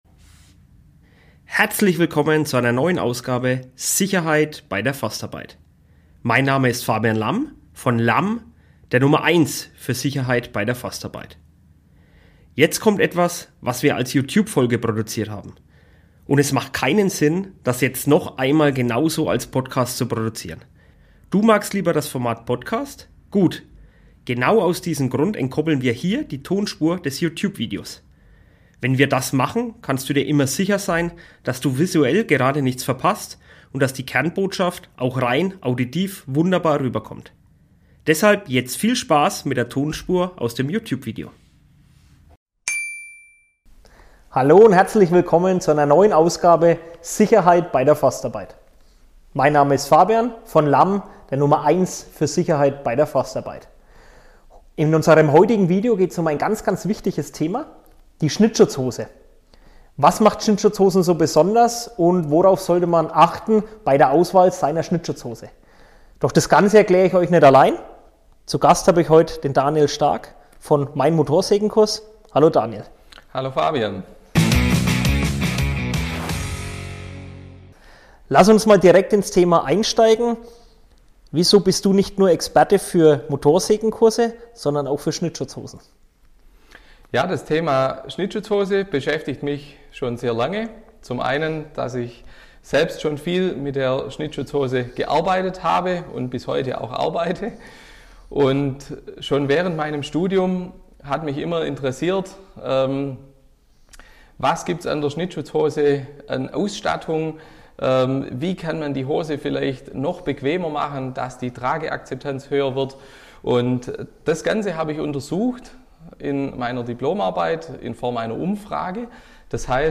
So findest Du die passende Schnittschutzhose - Interview